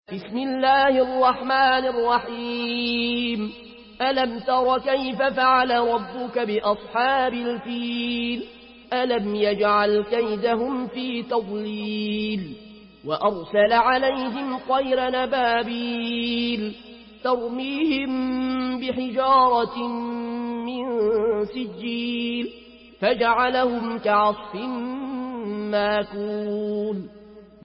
مرتل ورش عن نافع من طريق الأزرق